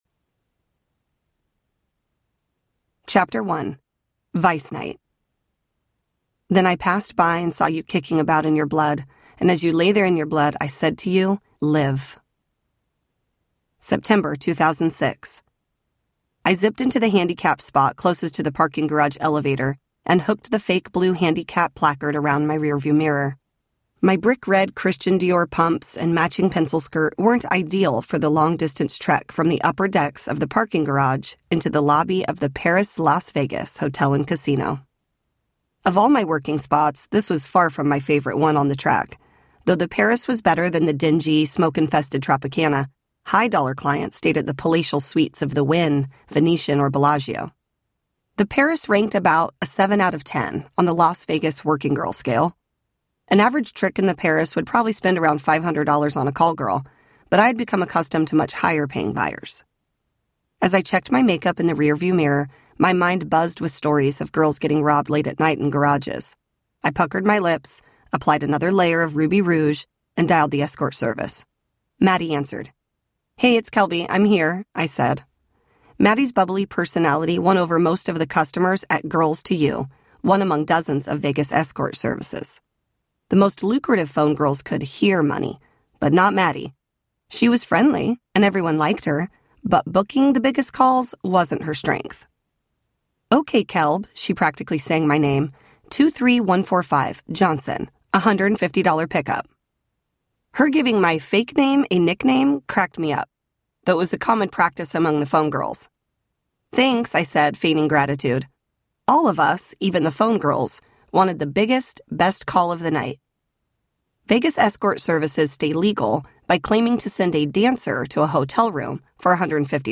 In Pursuit of Love Audiobook
Narrator
8.5 Hrs. – Unabridged